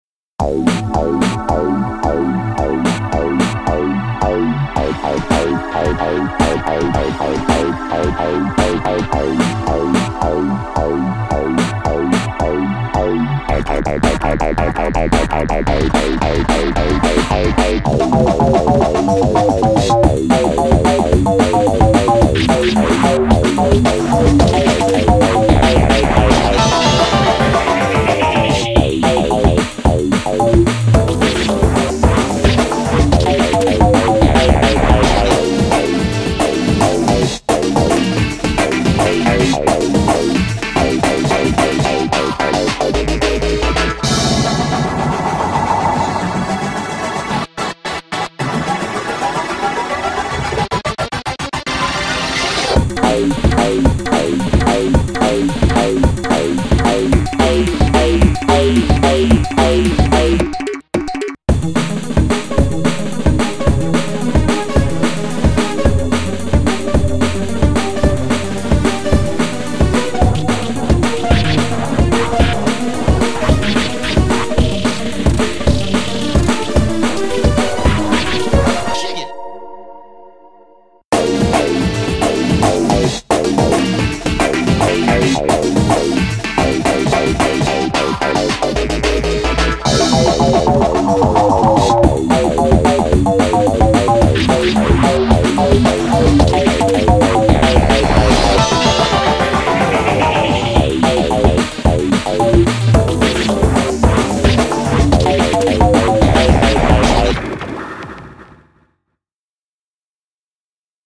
BPM220